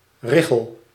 ريخل نطق هولندي: [ˈrɪɣəl]  (
Nl-richel.ogg